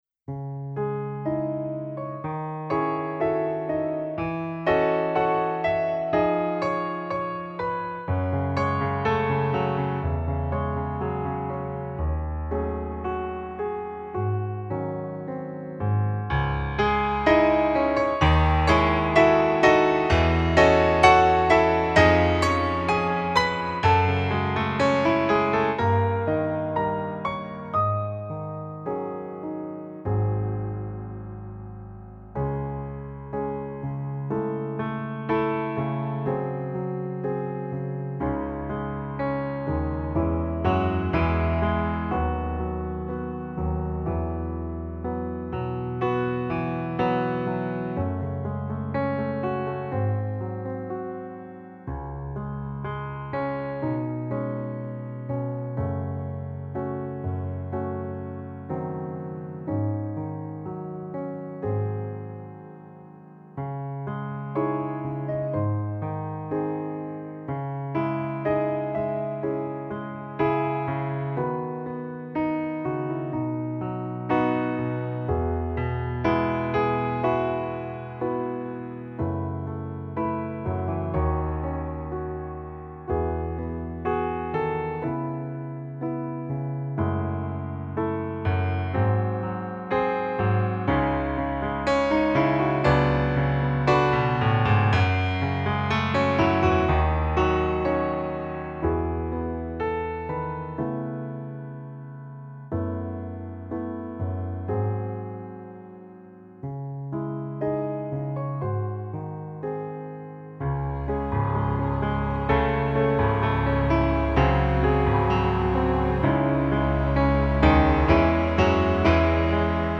Tempo: Normaal